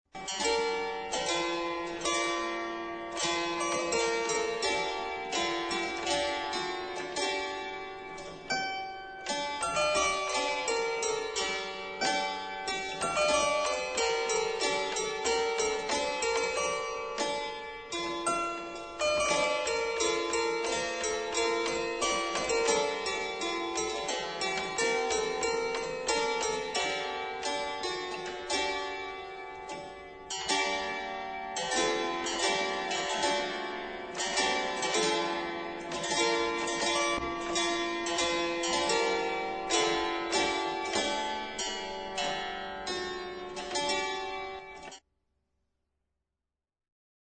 LE CLAVICIMBALUM
Notons que ces "sautereaux" ne possèdent pas encore d'étouffoirs.
Vous pouvez écouter cet instrument dans une Improvisation sur un mode médiéval en cliquant ici.